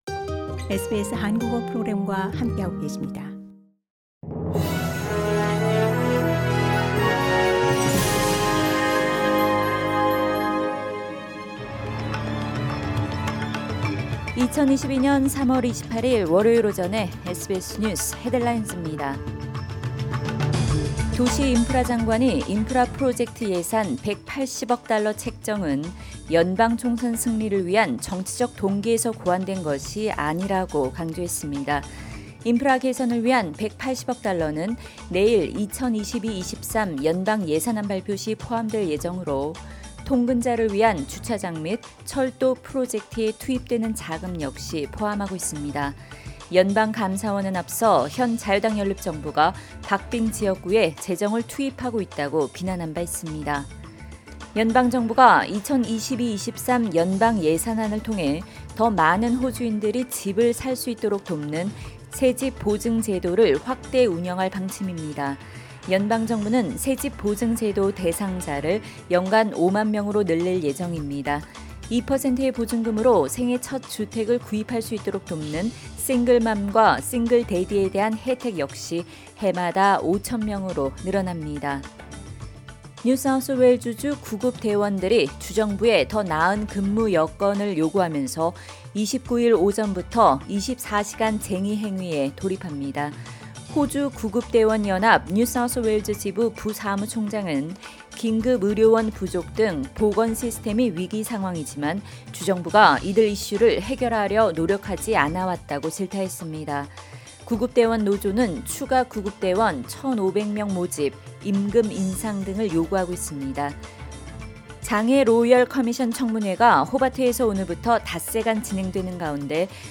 2022년 3월 28일 월요일 오전 SBS 뉴스 헤드라인즈입니다.